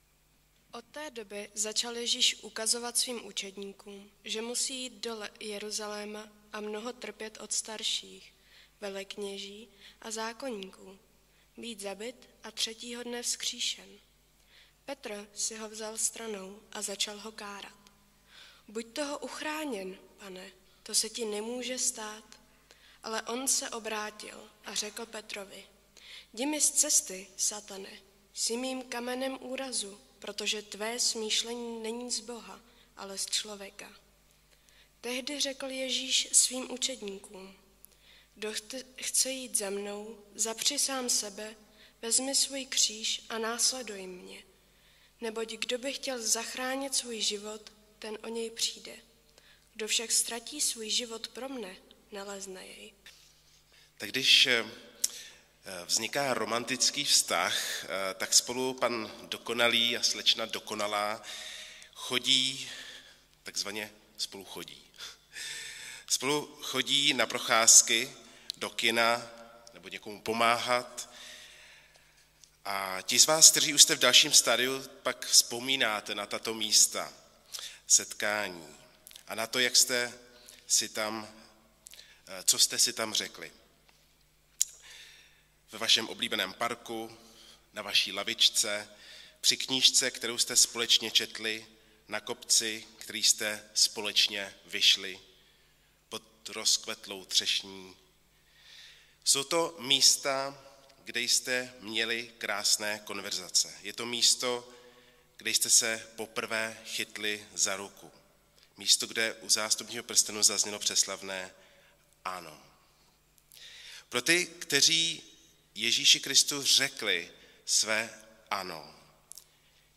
Nedělní kázání – 6.3.2022 Následování